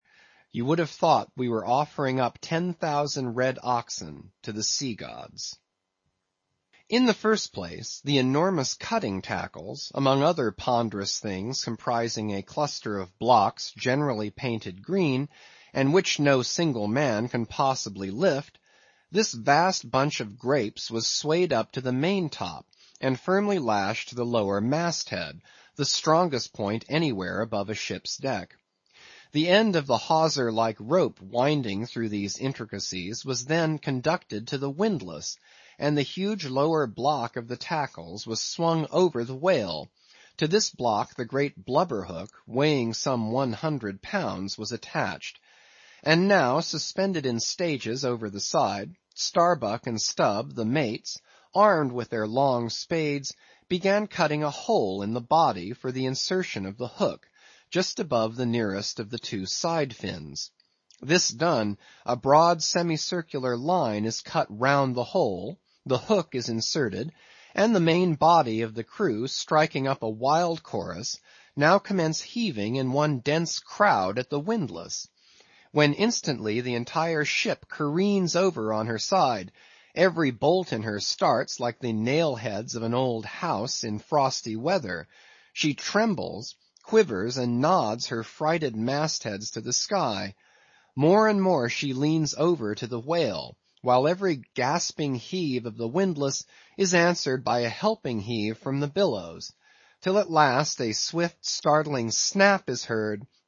英语听书《白鲸记》第629期 听力文件下载—在线英语听力室